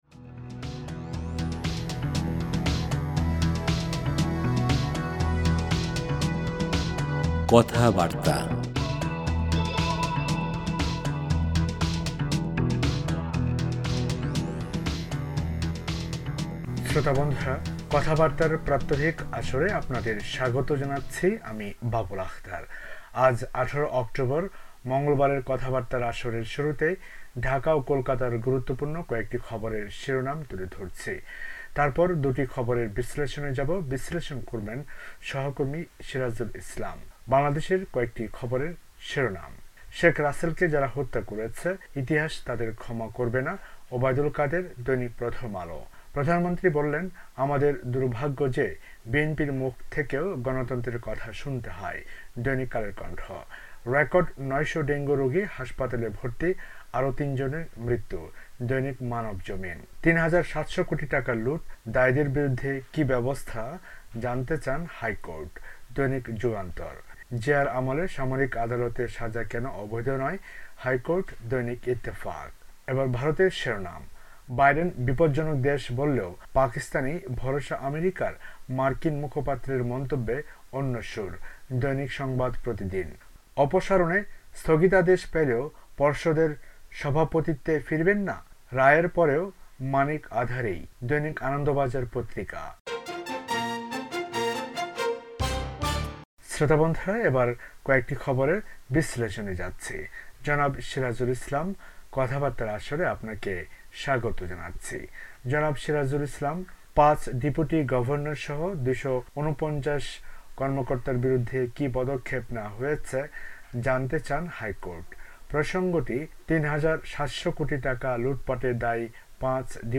বাংলাদেশ ও ভারতের পত্রপত্রিকার গুরুত্বপূর্ণ খবর